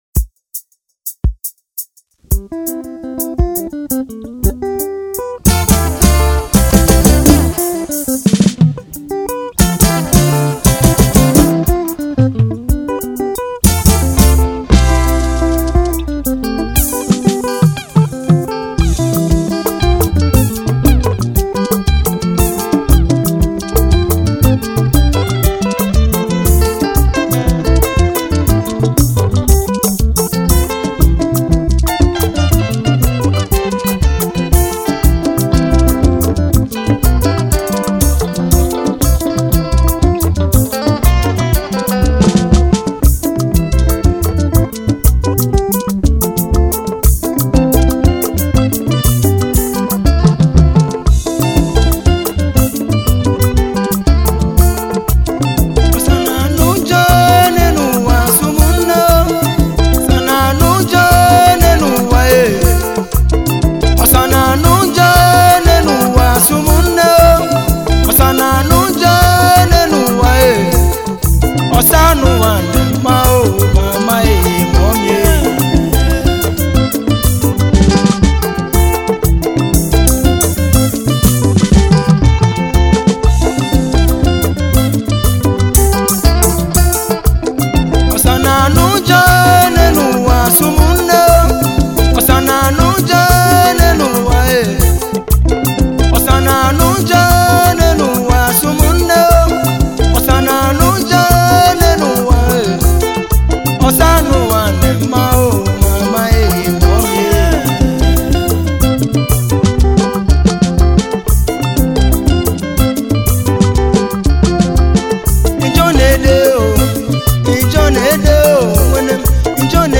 Nigeria talented Ukwuani singer and songwriter